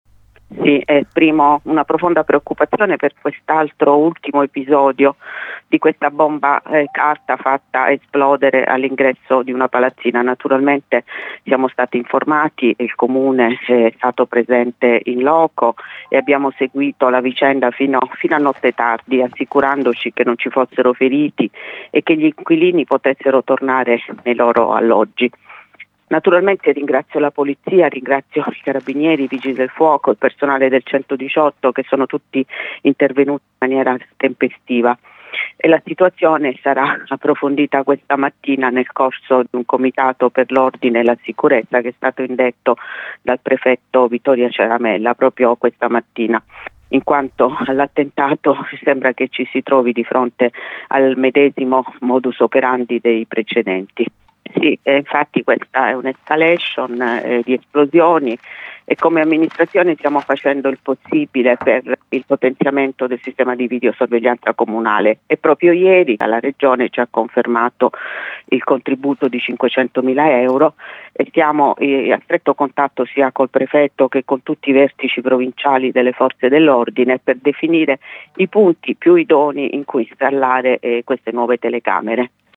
sindaco_bomba_kennedy.mp3